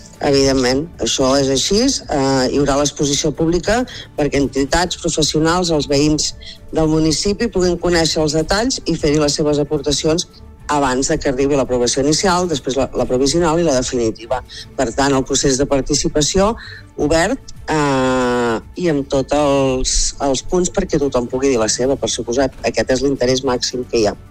Supermatí - entrevistes
I per parlar de la organització d’aquest POUM i d’aquesta aprovació hem parlat al Supermatí amb l’alcaldessa de Begur i Esclanyà, Maite Selva.